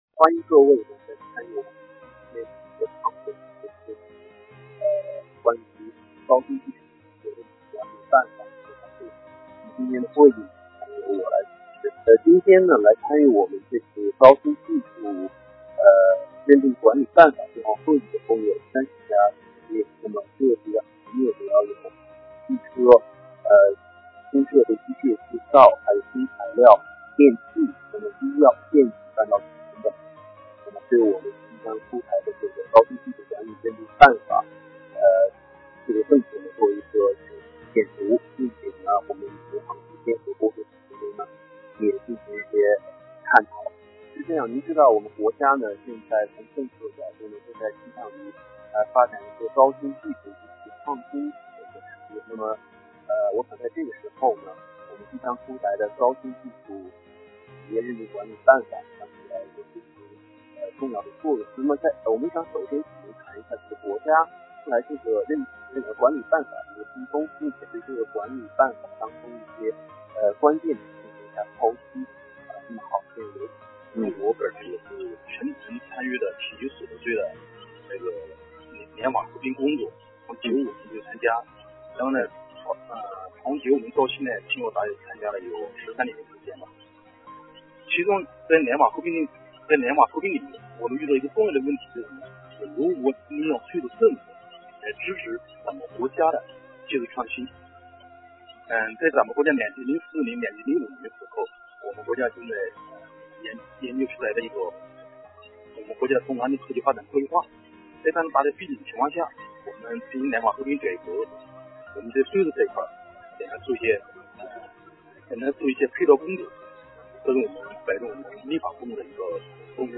电话会议
在这个电话会议中已经事先观测了高新技术企业认定管理办法。政府官员也回答了企业所关心的一些问题。